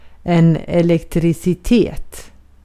Ääntäminen
Ääntäminen US : IPA : [ˌiː.lekˈtrɪs.ɪ.ti] UK : IPA : /ˌiːlekˈtɹɪsɪti/ IPA : /ɪˌlɛkˈtɹɪsɪti/ IPA : /ˌɛlɪkˈtɹɪsɪti/ US : IPA : /əˌlɛkˈtɹɪsɪti/ IPA : /iˌlɛkˈtɹɪsɪti/ IPA : /ɪˌlɛkˈt͡ʃɹɪsɪti/ Lyhenteet ja supistumat (laki) Elec.